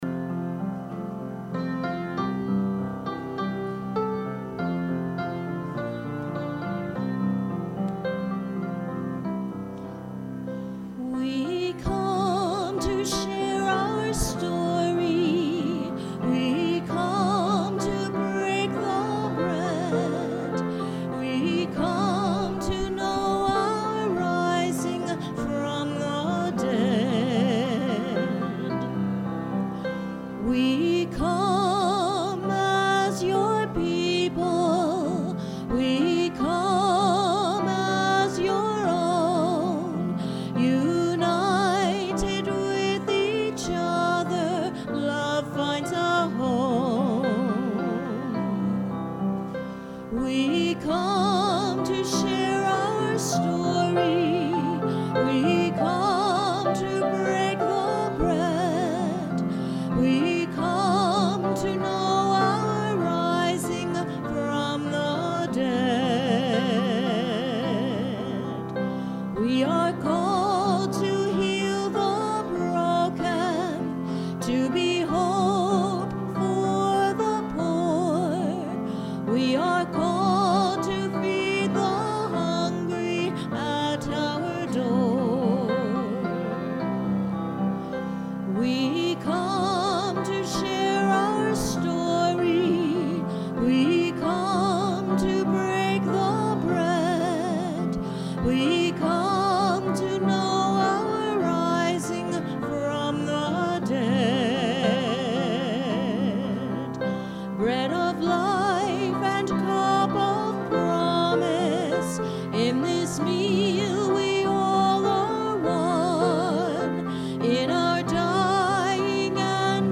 Sermon – April 14, 2019 – Advent Episcopal Church